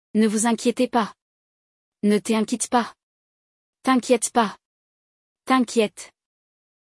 A pronúncia é algo como “tã-kyèt”, com um som nasal no início.